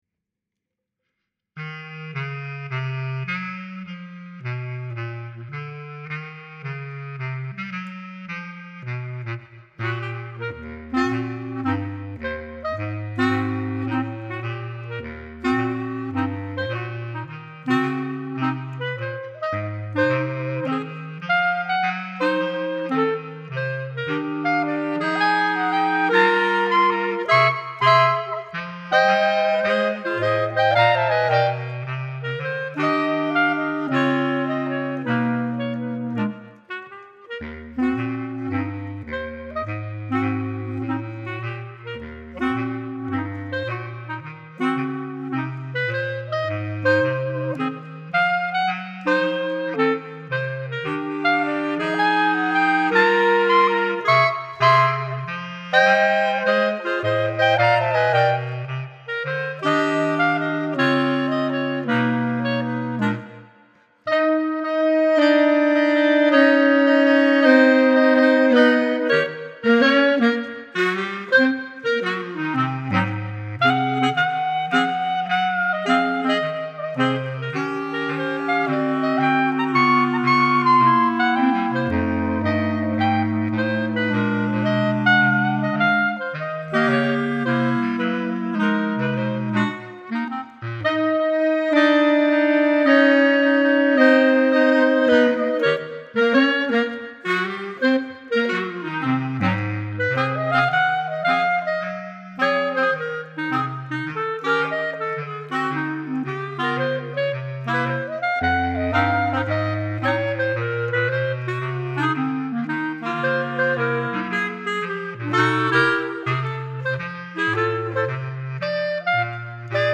Bb Clarinet Range: E1 to Eb4.  Bass Clarinet Lowest Note: F1